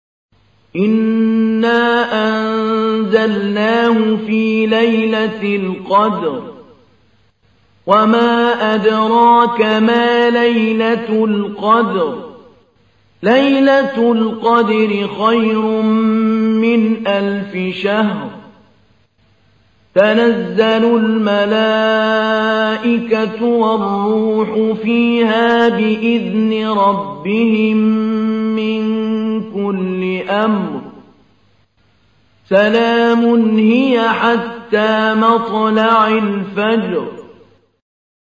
تحميل : 97. سورة القدر / القارئ محمود علي البنا / القرآن الكريم / موقع يا حسين